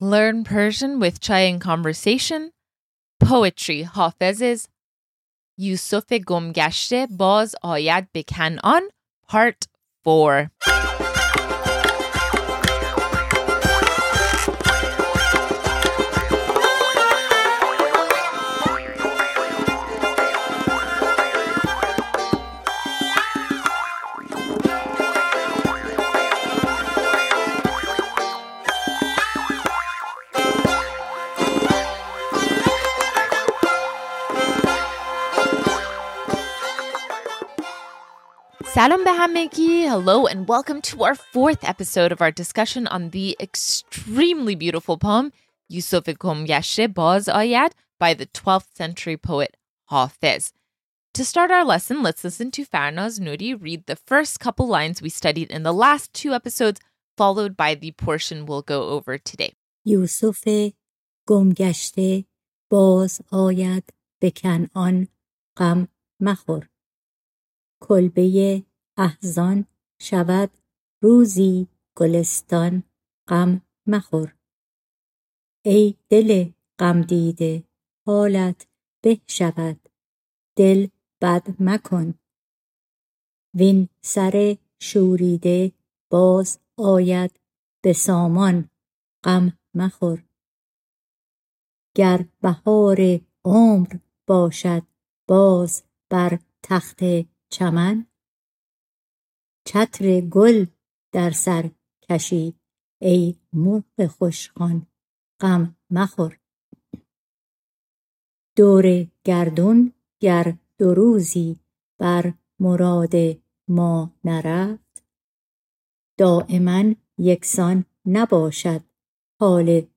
In this lesson, we go over more words and phrases in the next four lines of the poem yoosofé gom gashté by Hafez.